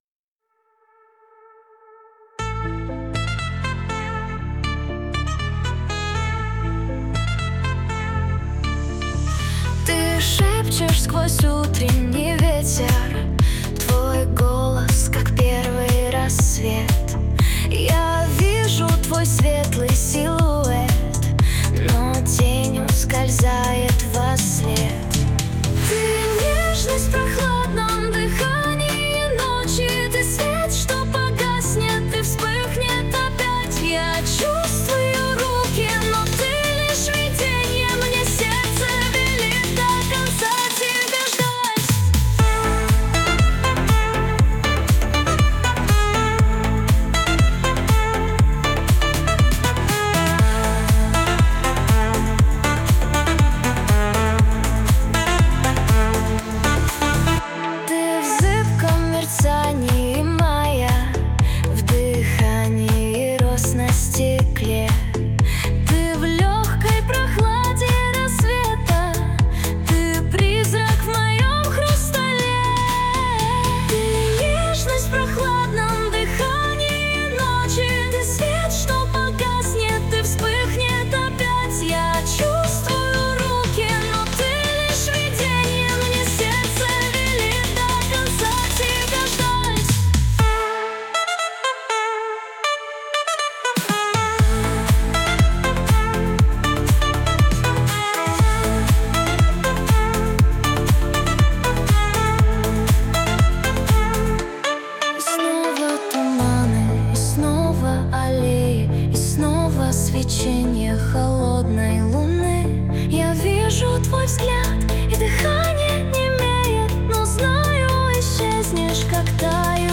RUS, Romantic, Lyric, Dance, Pop | 17.03.2025 16:42